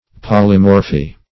\Pol"y*mor`phy\